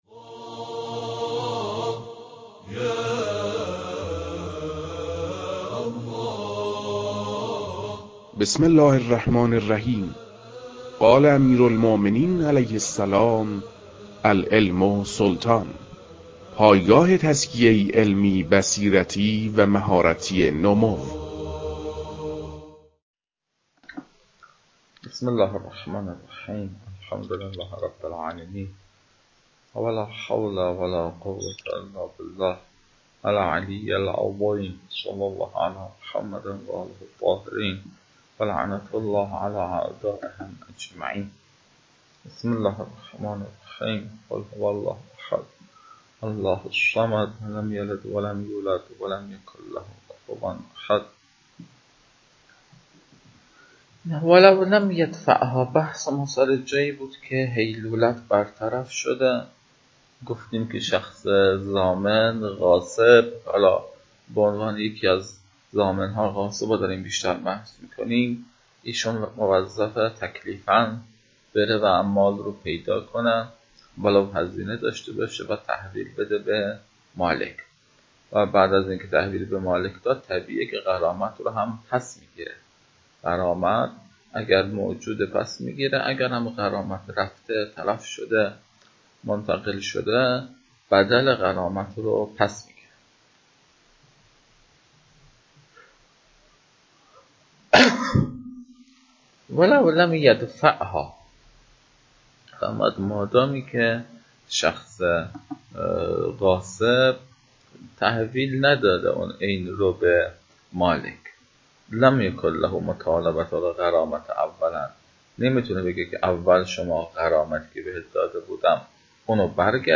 فایل های مربوط به تدریس مباحث تنبیهات معاطات